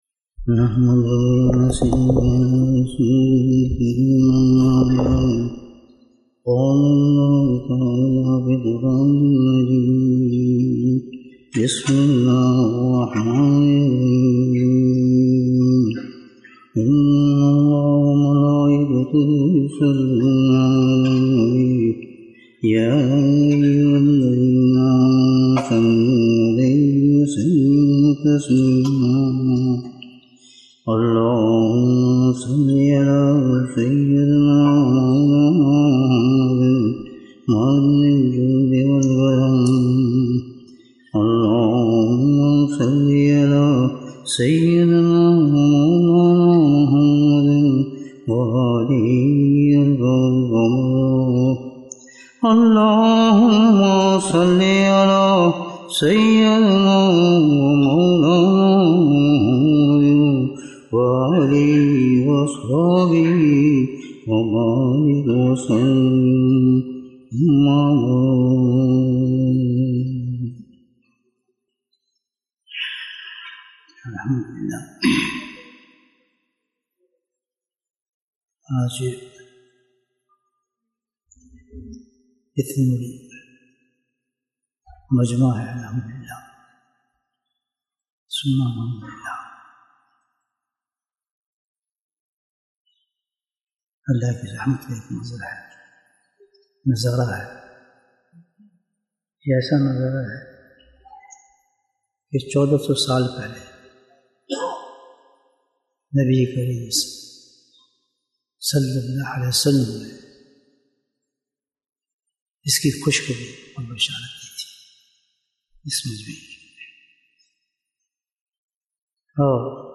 Bayan
Annual Ijtema 2024